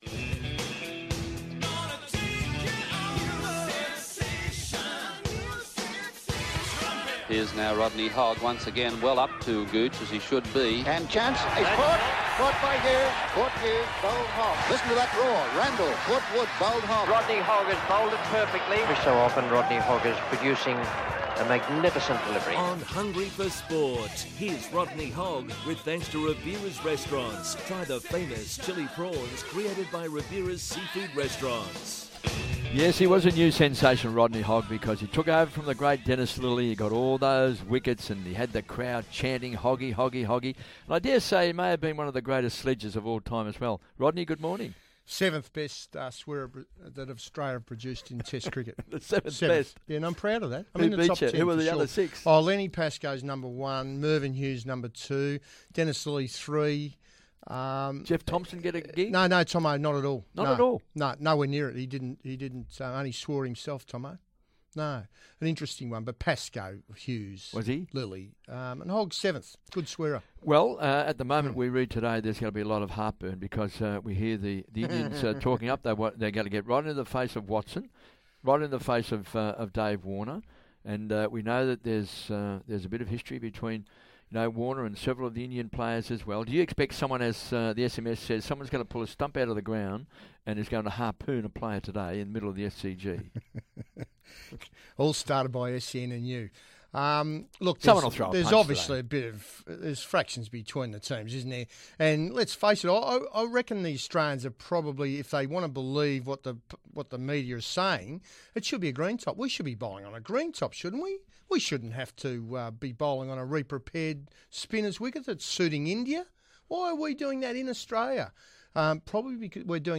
Rodney Hogg joins KB in the studio previewing today's Semi Final between Australia vs. India.